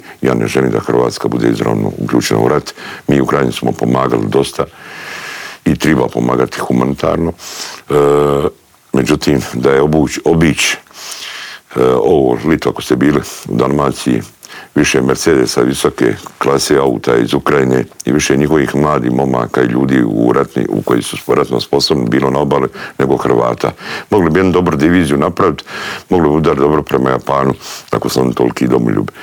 Njihov kandidat Miro Bulj bio je gost u Intervjuu tjedna Media servisa i otkrio zašto se kandidirao i po čemu se izdvaja od ostalih kandidata.
Miro Bulj izjavio je u studiju Media servisa da se kandidirao za predsjednika države jer mu je ‘‘puna kapa‘‘ gledati kako Hrvatska demografski tone i kako se vladajući odnose prema Hrvatskoj vojsci.